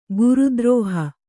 ♪ guru drōha